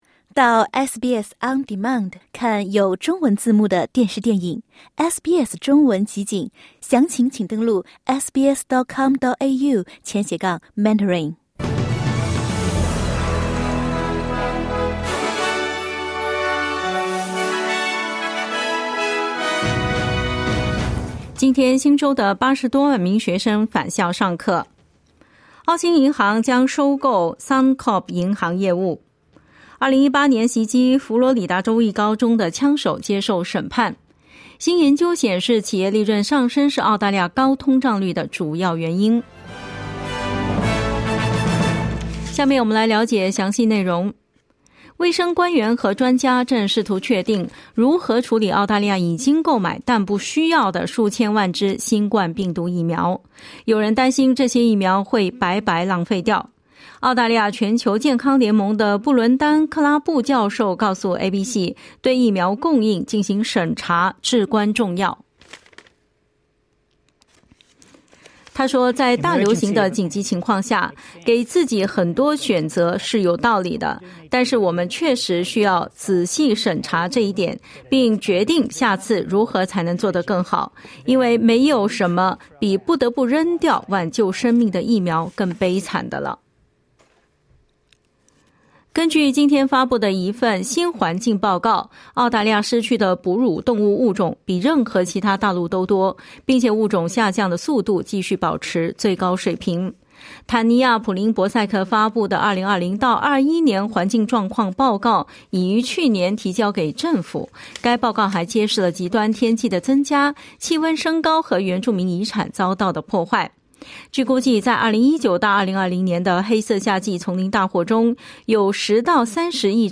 SBS早新闻（7月19日）